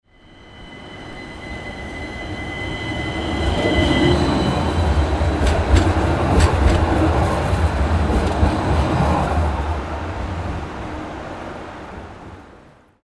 City Tram Passes By Sound Effect
Authentic close-up recording of a tram quickly passing through a city street.
City-tram-passes-by-sound-effect.mp3